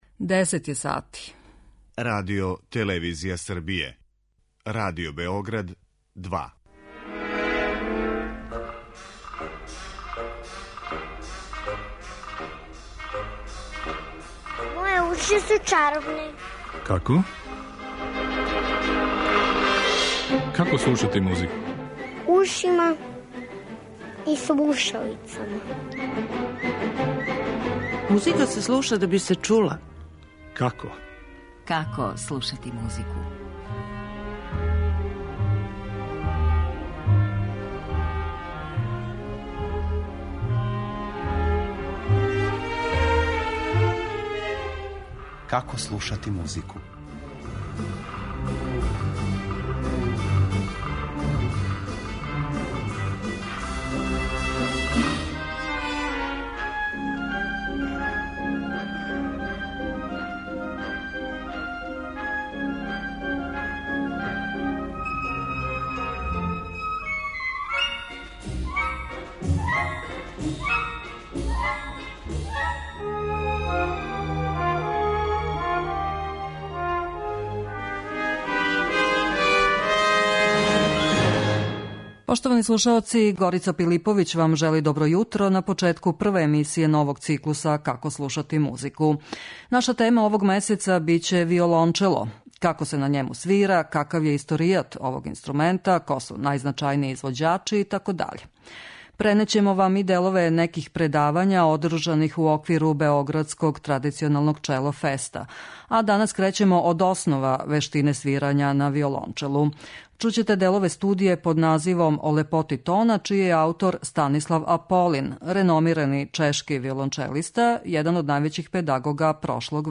Виолончело у првом плану
Чућемо како се на њему свира, какав је историјат овог инструмента, ко су најзначајнији извођачи итд.